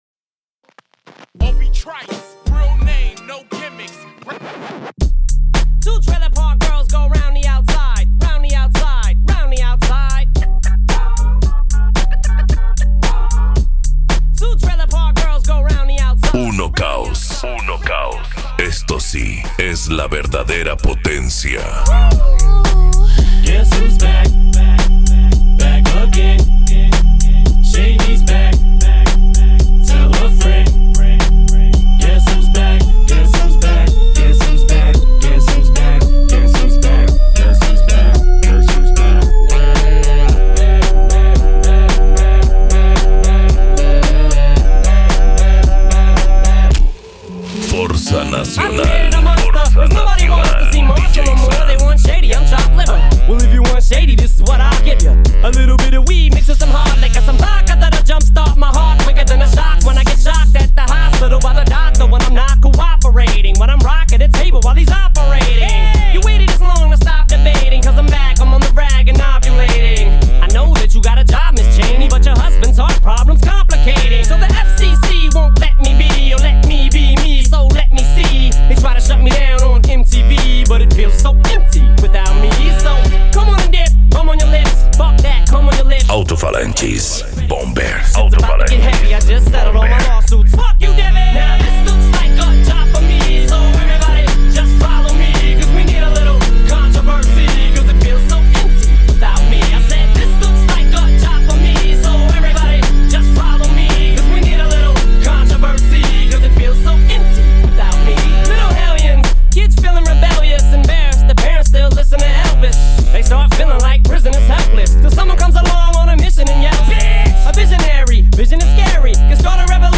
Bass
Musica Electronica
Remix